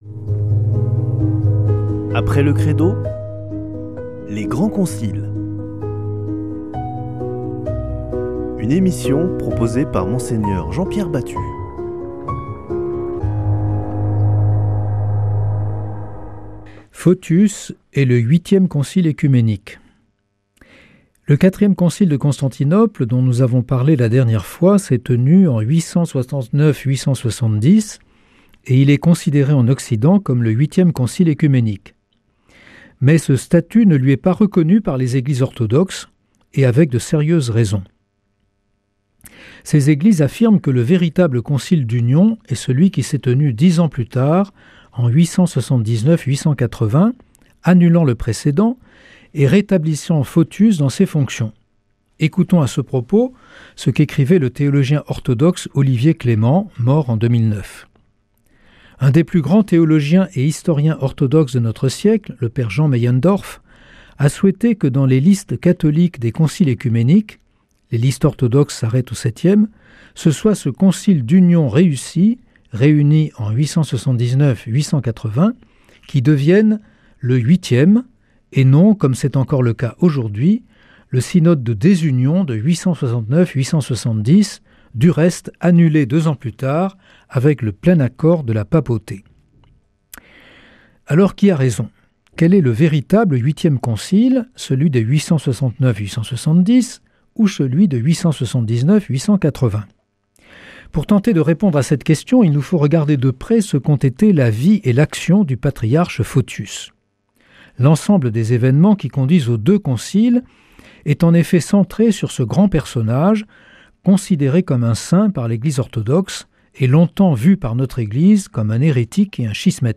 Partager Copier ce code (Ctrl+C) pour l'intégrer dans votre page : Commander sur CD Une émission présentée par Mgr Jean-Pierre Batut Evêque auxiliaire de Toulouse Voir la grille des programmes Nous contacter Réagir à cette émission Cliquez ici Qui êtes-vous ?